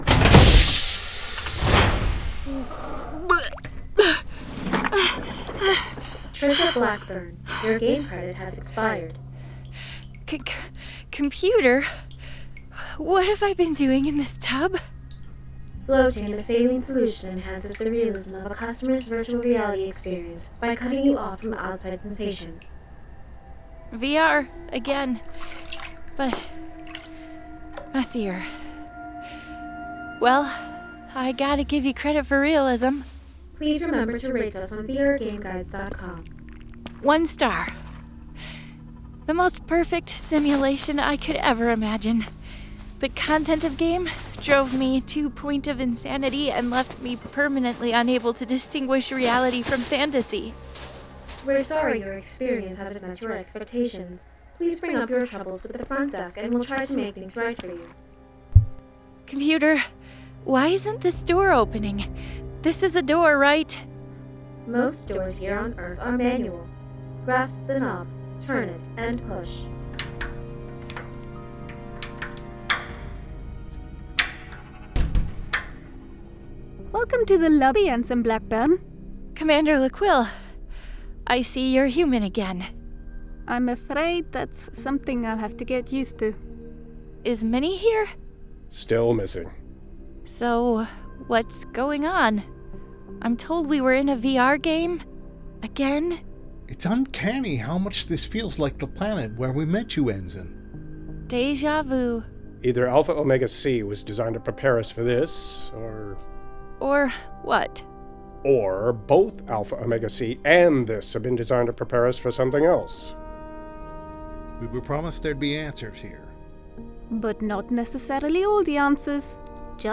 In what seems to be the far future, the crew of a Galactic Confederation spaceship must learn to distinguish fact from fiction before they go insane. Full cast science fiction stereo audio drama.